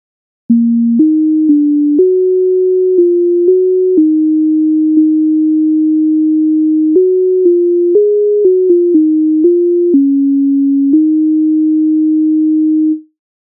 MIDI файл завантажено в тональності es-moll